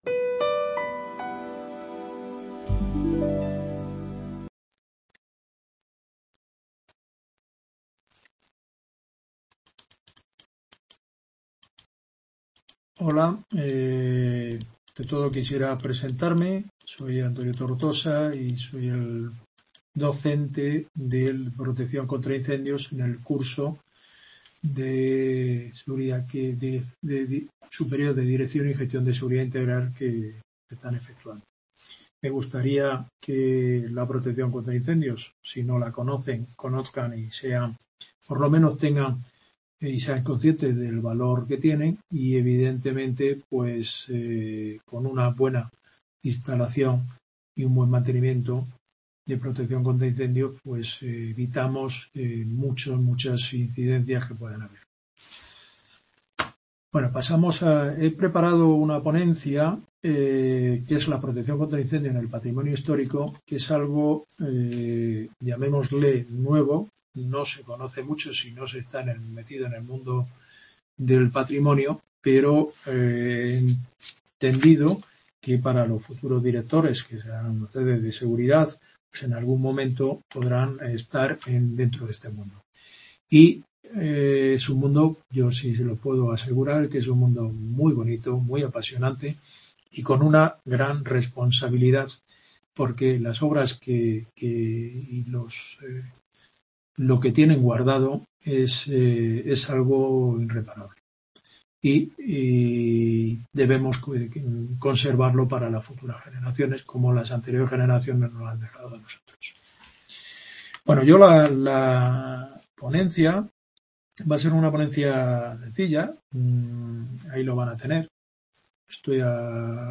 Ponencia sobre PCI en el patrimonio XIX